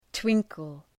Προφορά
{‘twıŋkəl}